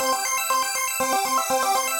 SaS_Arp02_120-C.wav